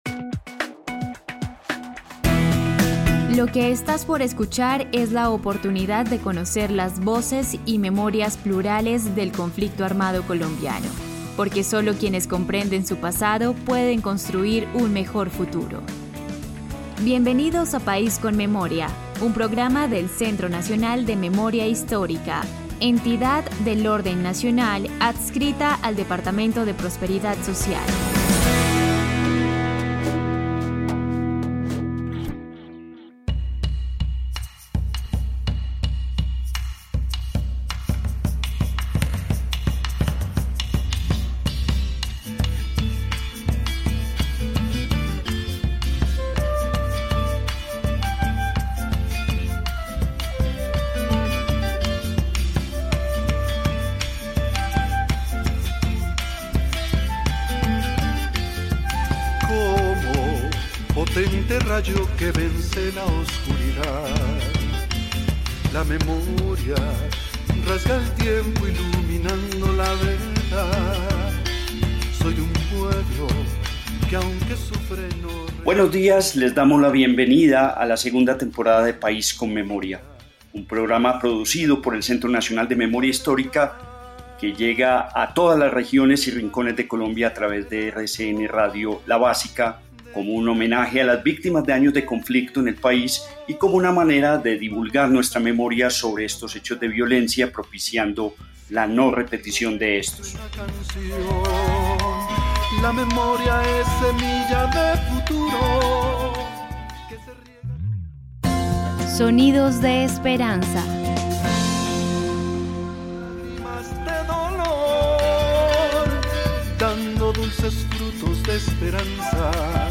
presentador.
presentadora.
cantante